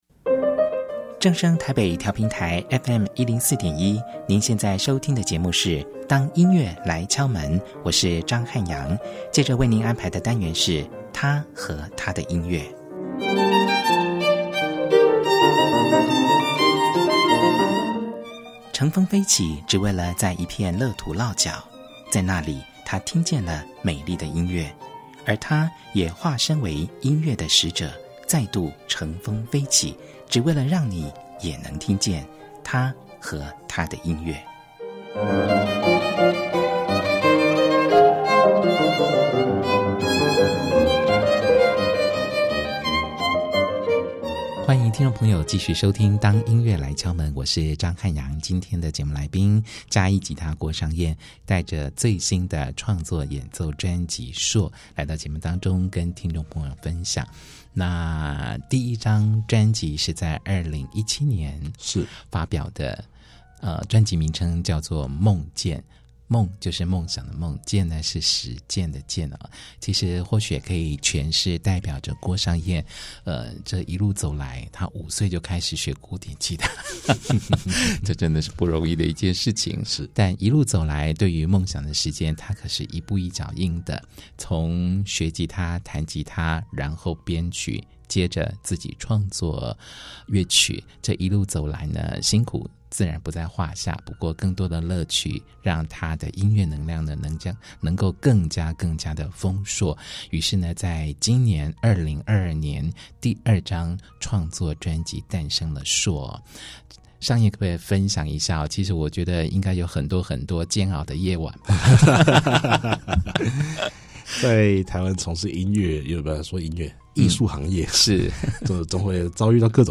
訪問古典吉他演奏家